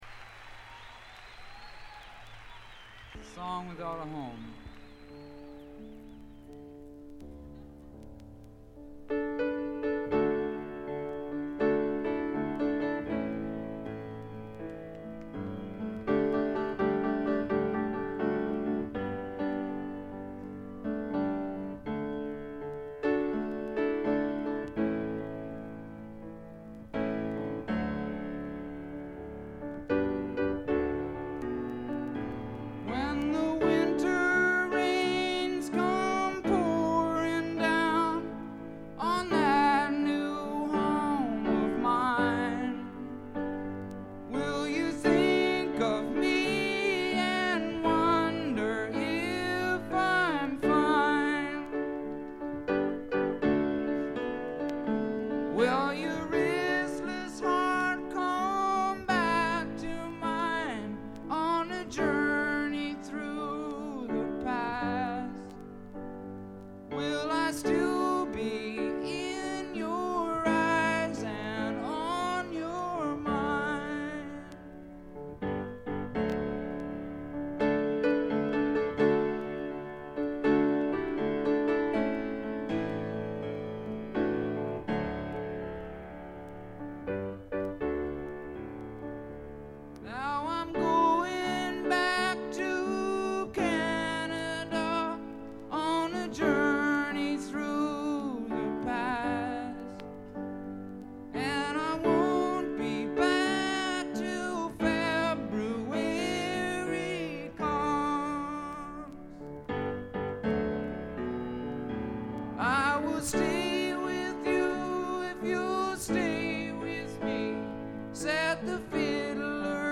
ライヴと言ってもこの時点ですべて未発表曲で固めた意欲作です。
試聴曲は現品からの取り込み音源です。
Recorded at Public Hall, Cleveland (February 11, 1973)